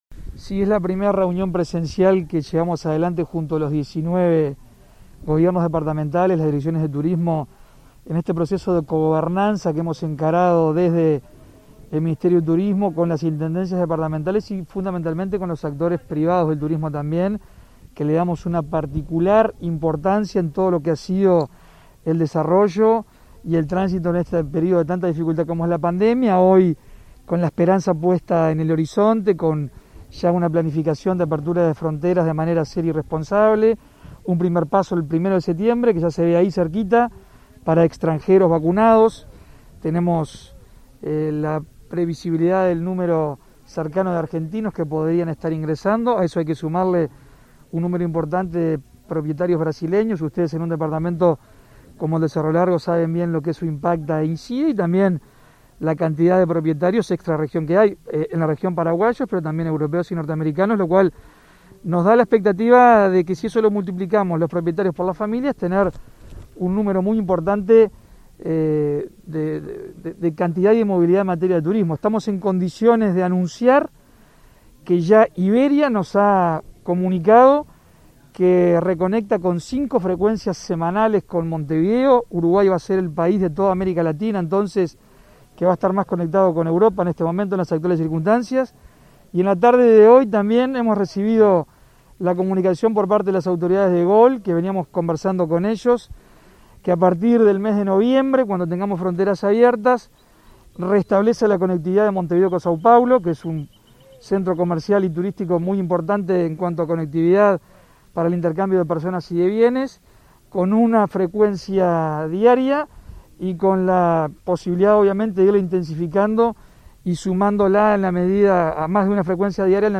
Declaraciones a la prensa del ministro de Turismo, Germán Cardoso
Declaraciones a la prensa del ministro de Turismo, Germán Cardoso 16/08/2021 Compartir Facebook X Copiar enlace WhatsApp LinkedIn El ministro Cardoso brindó declaraciones a la prensa una vez culminada la primera reunión presencial junto a directores de Turismo de las todas las intendencias. La actividad fue realizada este lunes 16 en Melo, Cerro Largo.